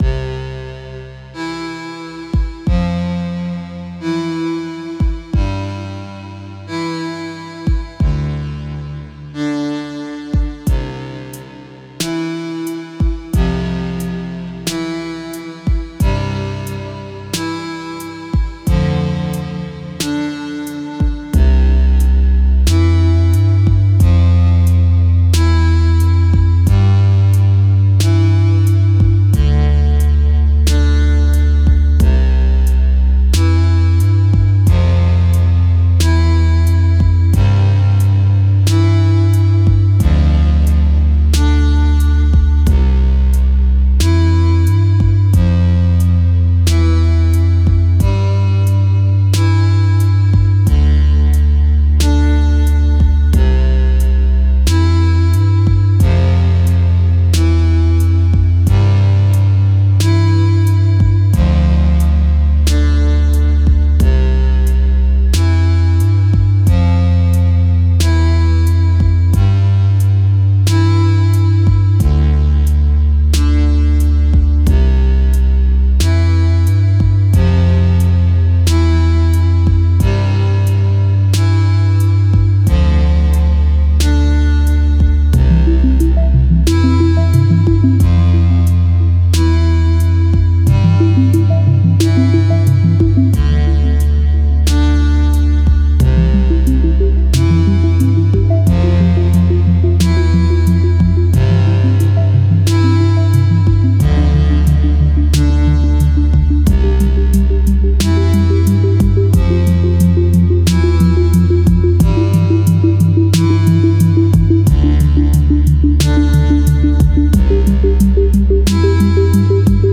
VIdeo Game Music.wav